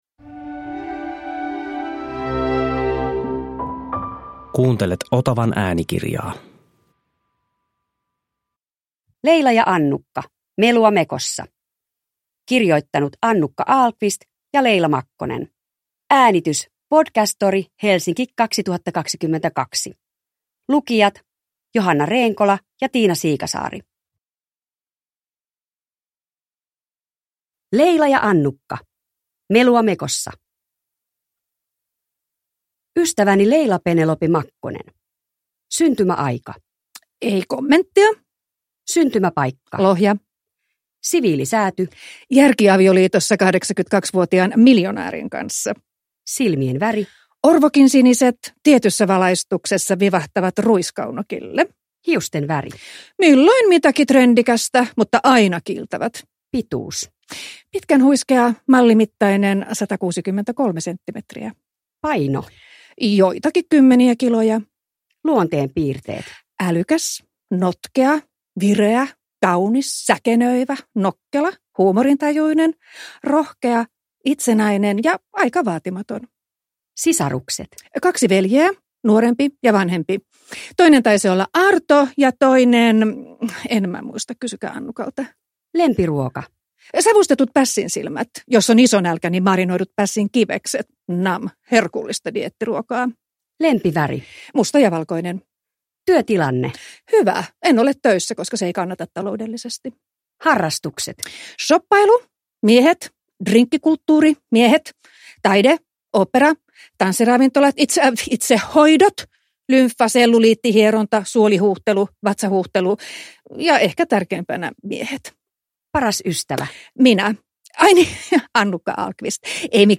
Produkttyp: Digitala böcker
Parasta ysäriä äänikirjoina!